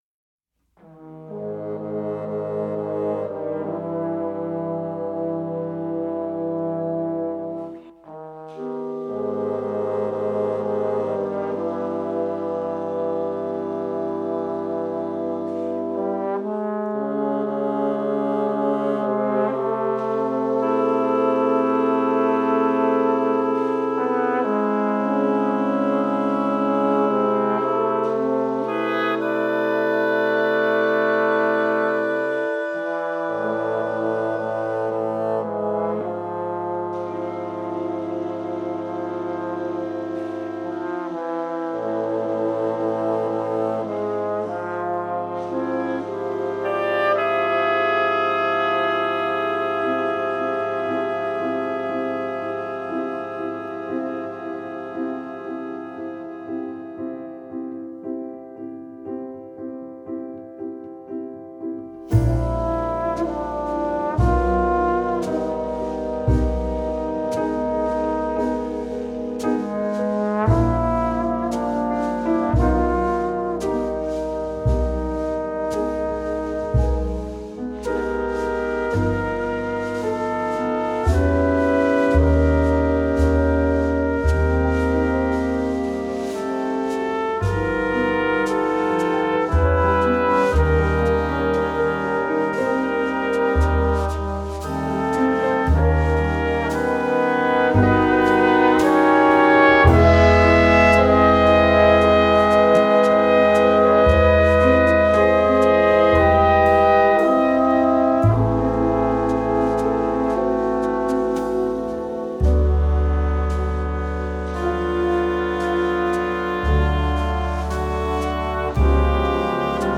undecet